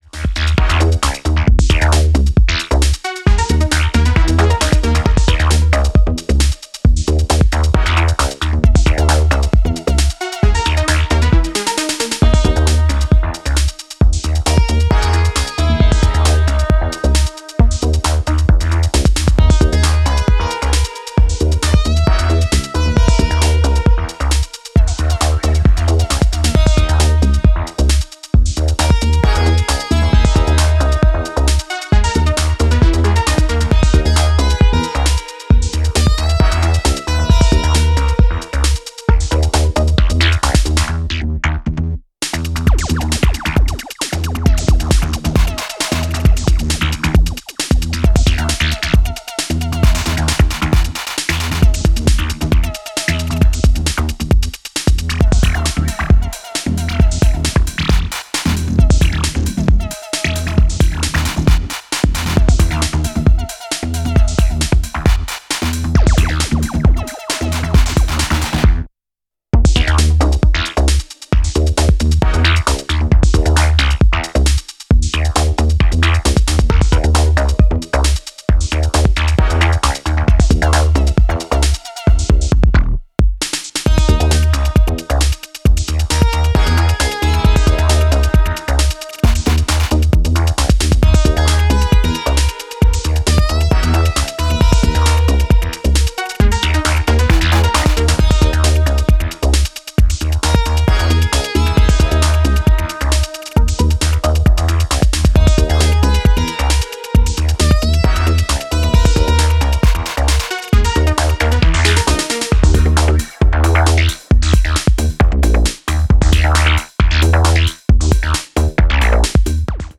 Electro House